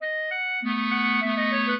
minuet8-4.wav